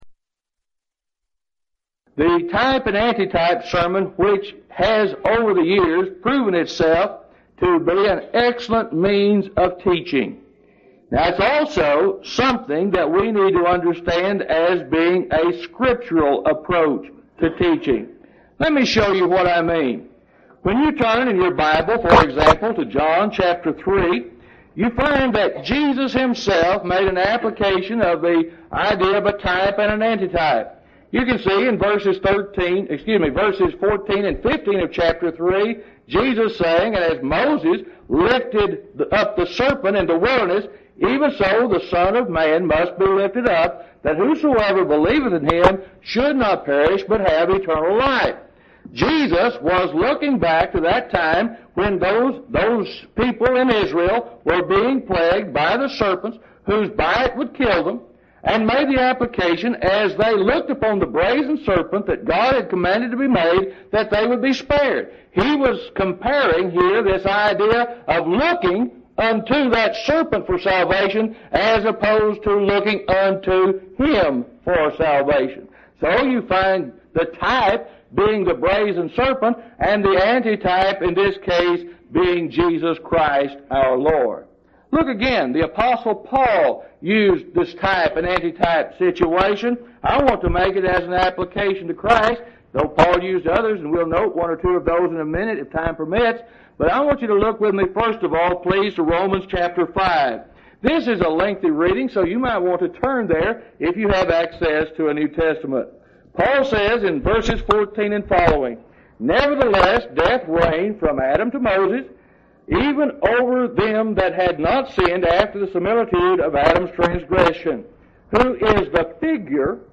Event: 2nd Annual Lubbock Lectures
lecture